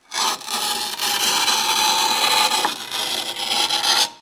Cortar azulejos
Sonidos: Industria
Sonidos: Hogar